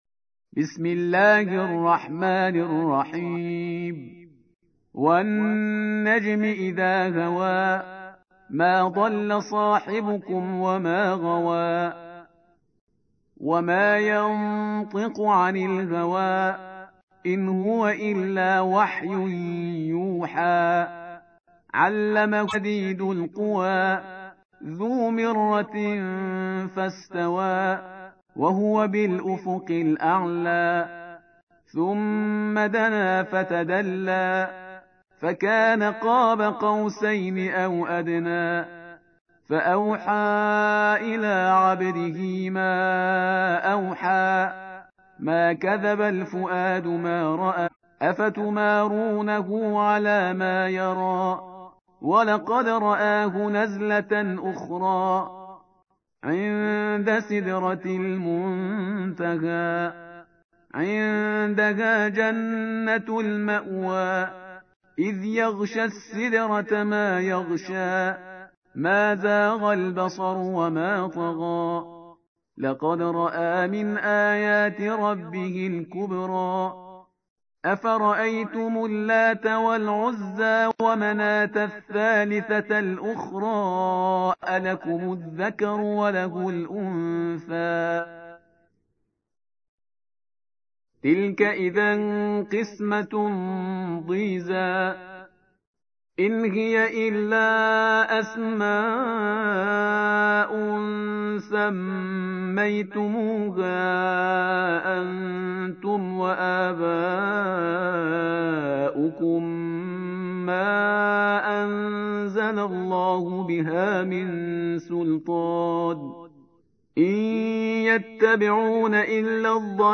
53. سورة النجم / القارئ